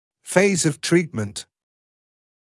[feɪz əv ‘triːtmənt][фэйз эв ‘триːтмэнт]фаза лечения, этап лечения